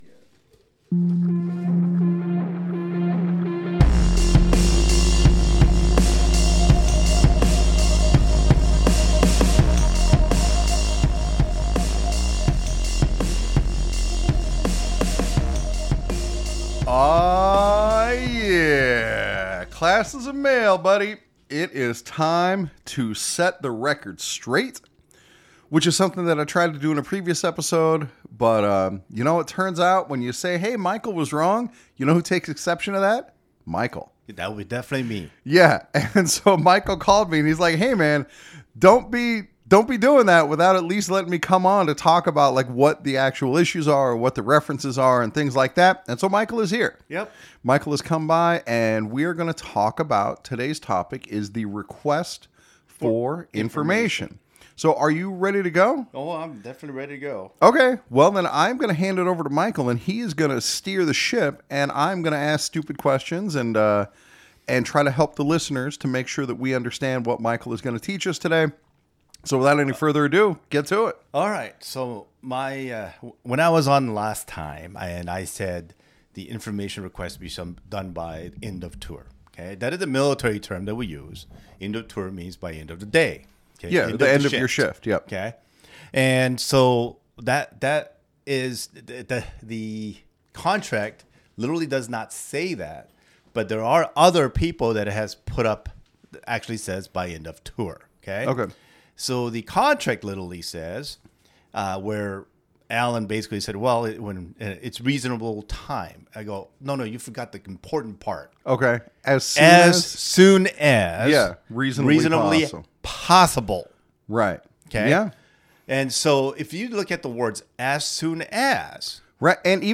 He's fired up!